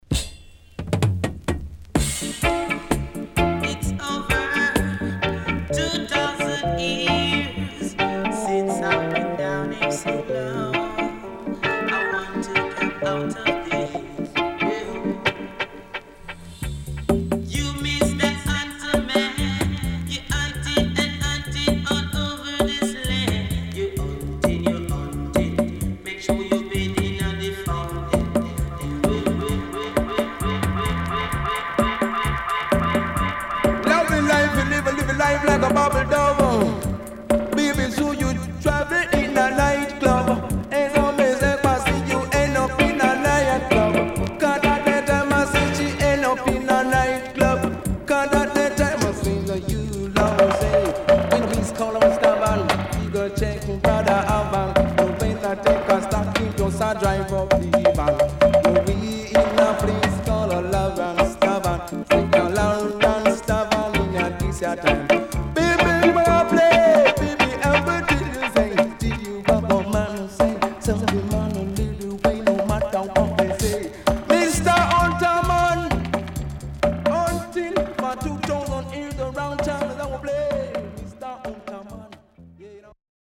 HOME > Back Order [VINTAGE DISCO45]  >  KILLER & DEEP
SIDE A:所々チリノイズ入ります。